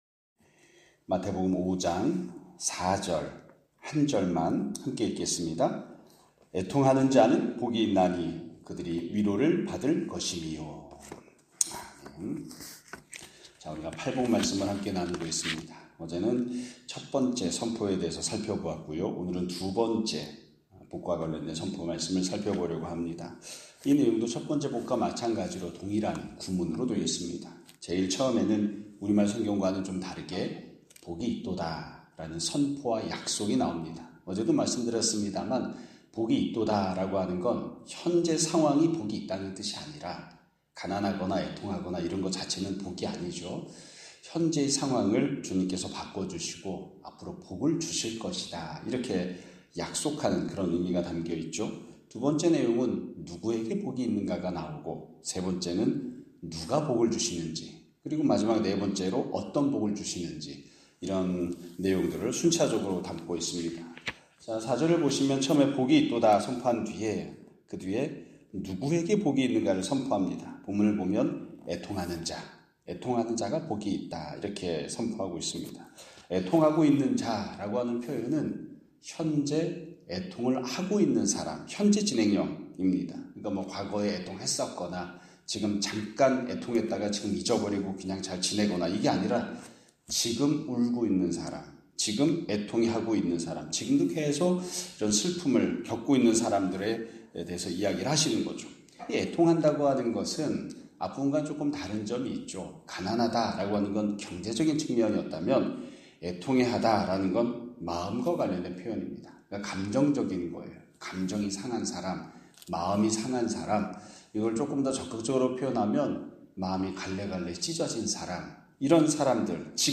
2025년 5월 8일(목 요일) <아침예배> 설교입니다.